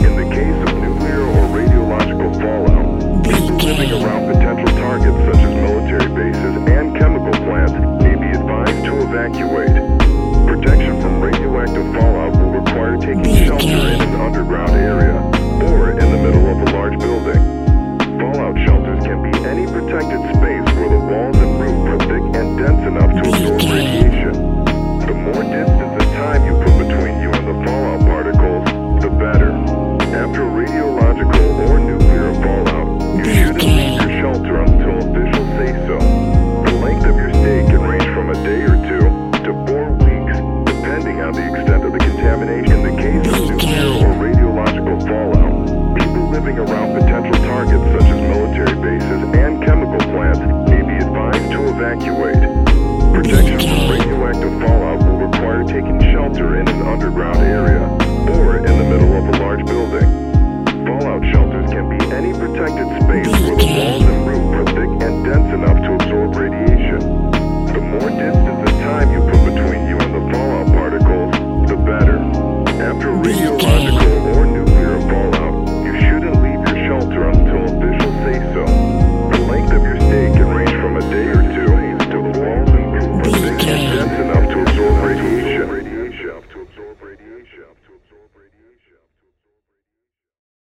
Ionian/Major
F♯
chilled
laid back
Lounge
sparse
new age
chilled electronica
ambient
atmospheric